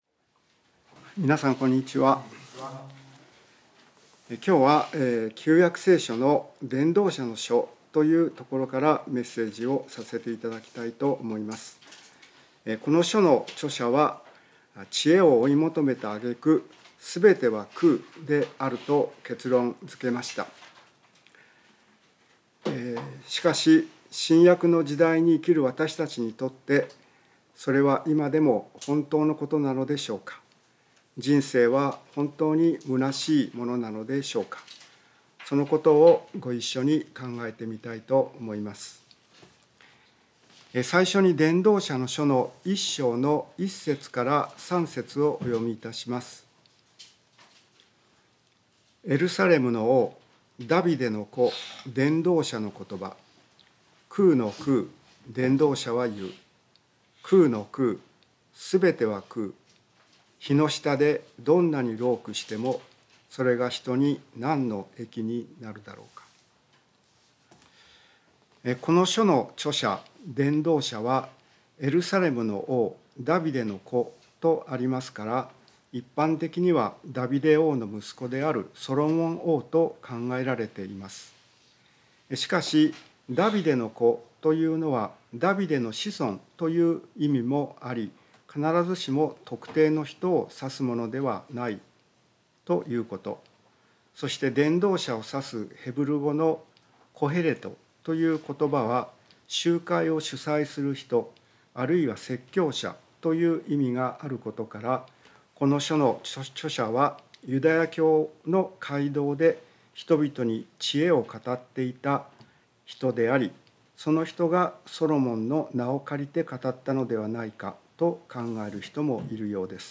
聖書メッセージ No.267